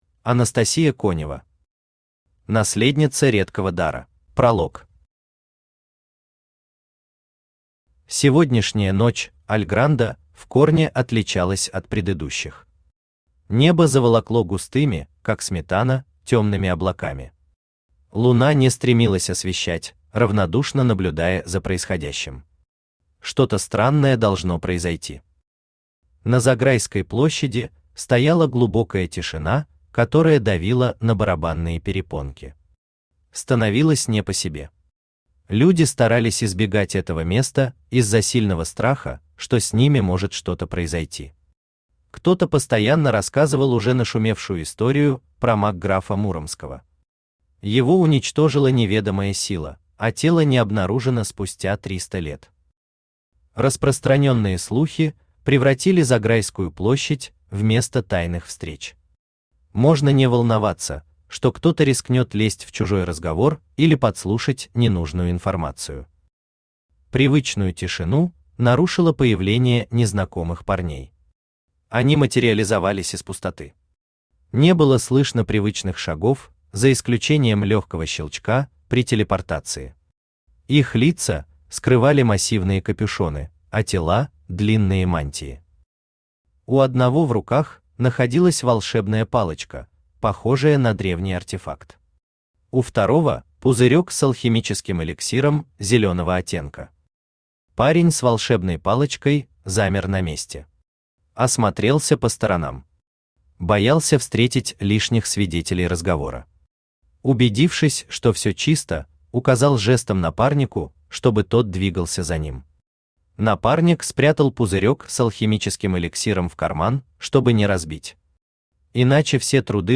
Аудиокнига Наследница редкого дара | Библиотека аудиокниг
Aудиокнига Наследница редкого дара Автор Анастасия Игоревна Конева Читает аудиокнигу Авточтец ЛитРес.